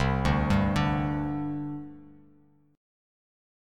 Csus2 chord